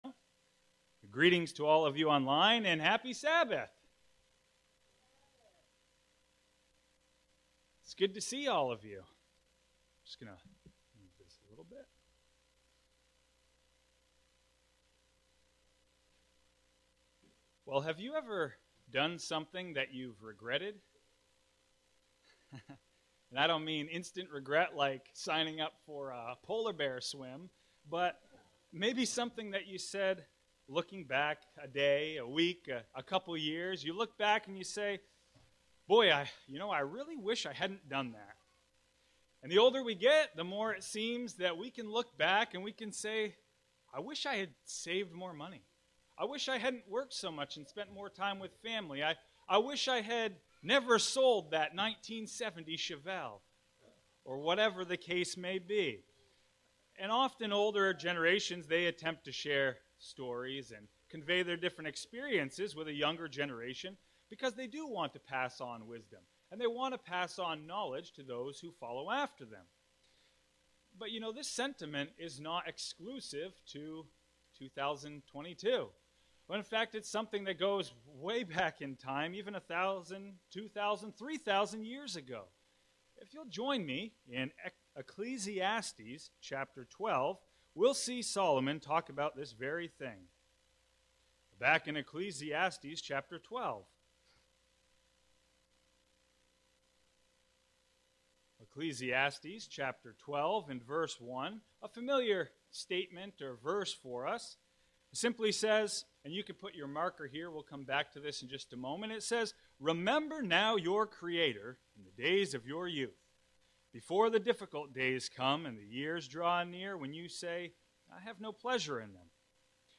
Sermons
Given in Fargo, ND